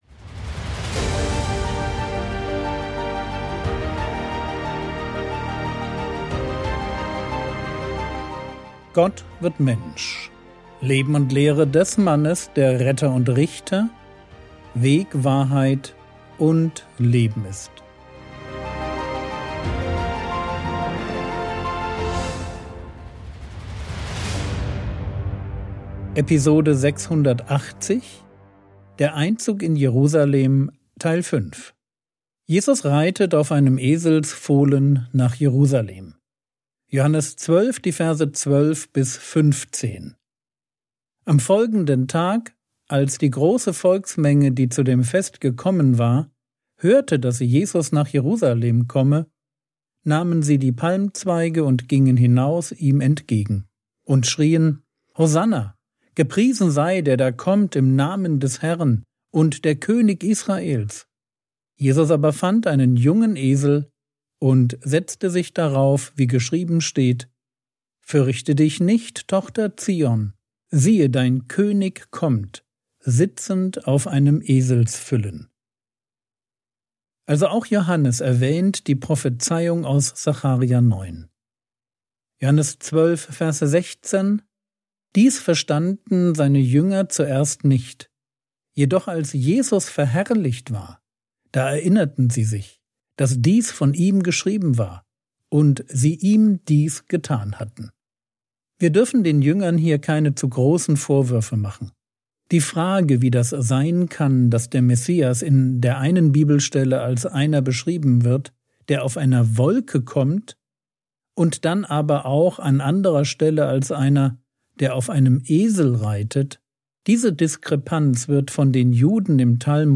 Episode 680 | Jesu Leben und Lehre ~ Frogwords Mini-Predigt Podcast